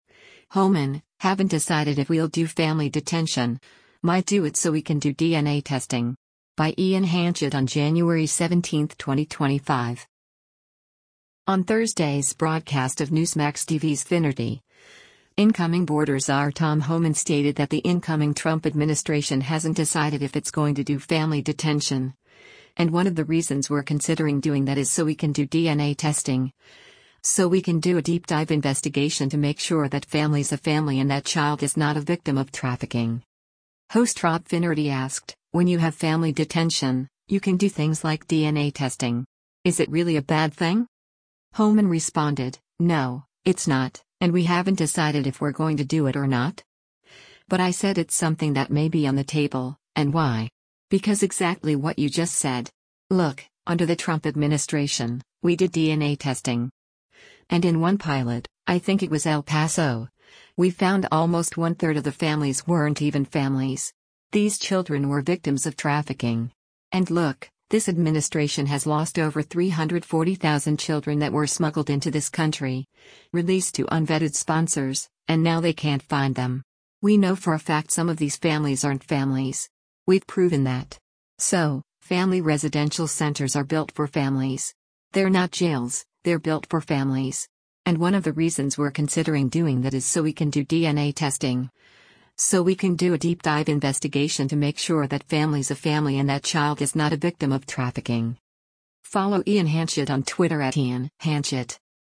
On Thursday’s broadcast of Newsmax TV’s “Finnerty,” incoming Border Czar Tom Homan stated that the incoming Trump administration hasn’t decided if it’s going to do family detention, and “one of the reasons we’re considering doing that is so we can do DNA testing, so we can do a deep dive investigation to make sure that family’s a family and that child is not a victim of trafficking.”
Host Rob Finnerty asked, “When you have family detention, you can do things like DNA testing. Is it really a bad thing?”